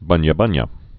(bŭnyə-bŭnyə)